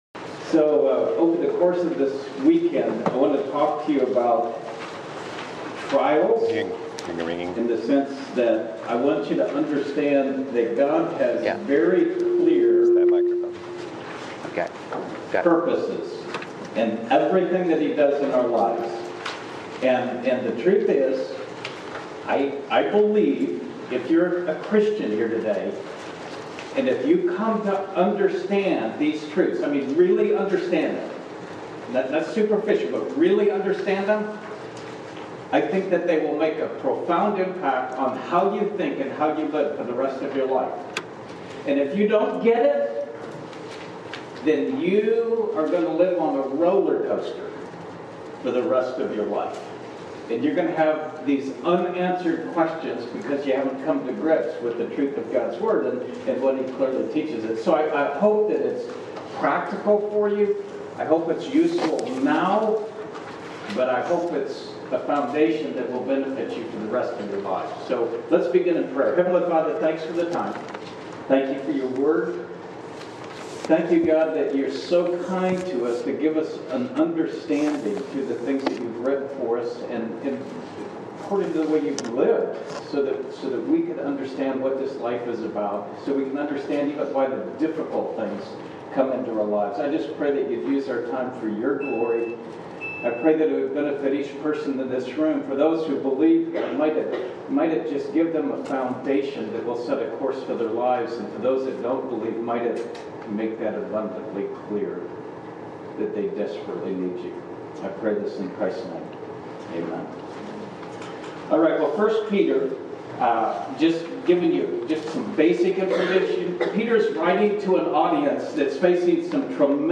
High School HS Winter Camp - 2018 Audio Series List Next ▶ Current 1.